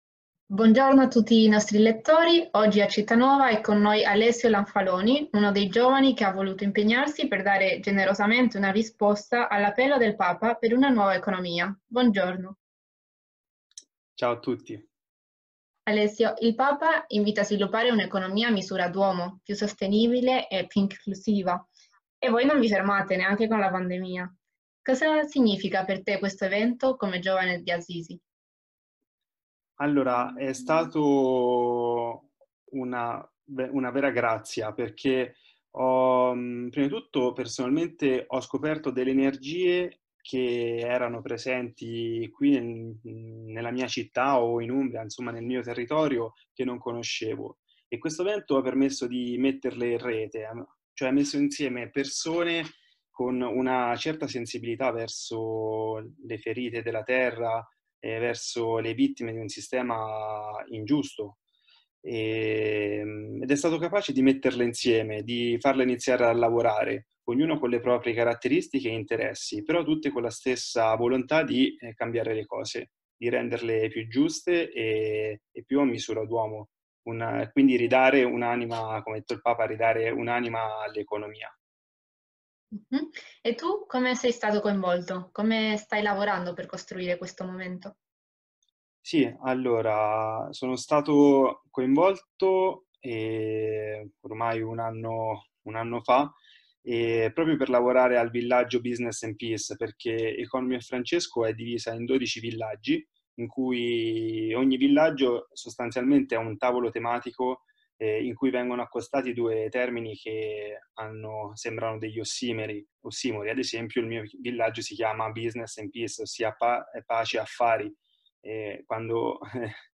il podcast dell'intervista